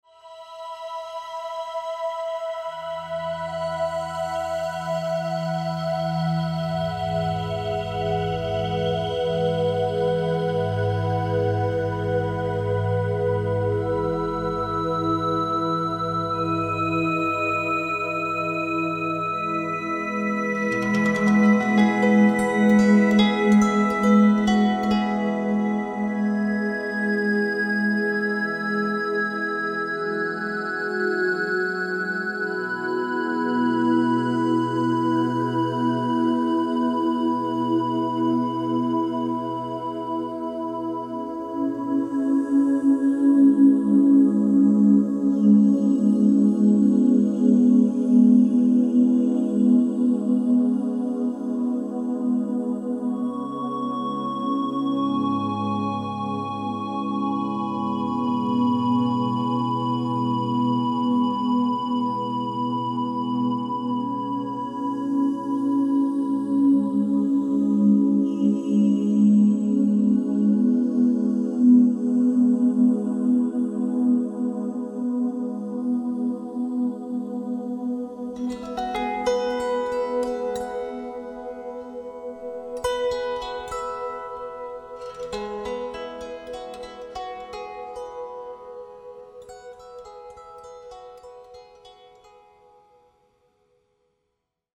A powerful mixture of electronic and acoustic ambient music